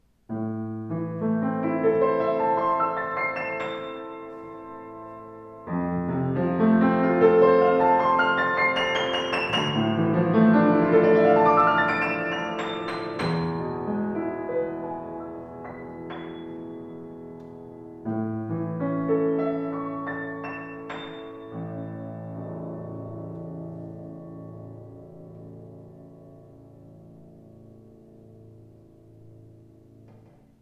Voller, gestaltungsfähiger Klang, angenehm flüssige Spielart.